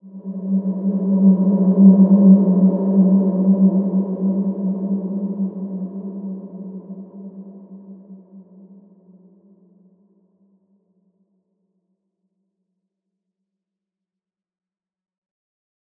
Large-Space-G3-f.wav